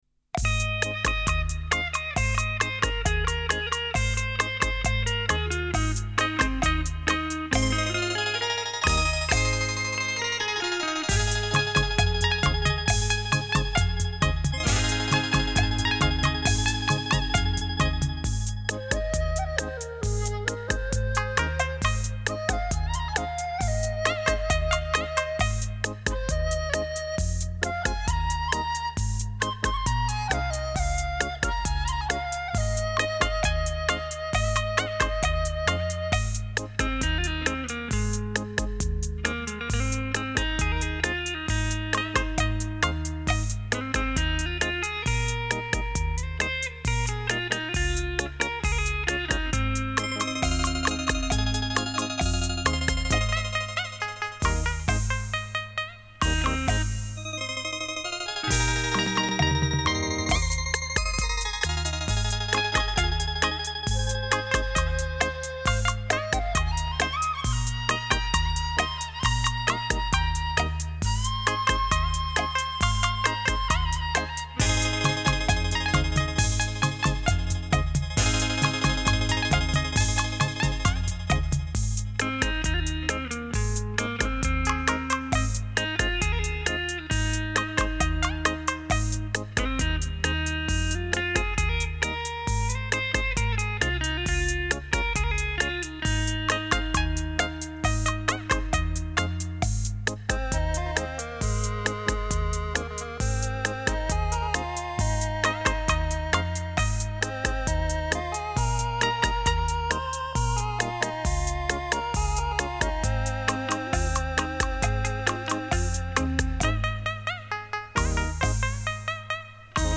非常的畅通，舒服，爽快。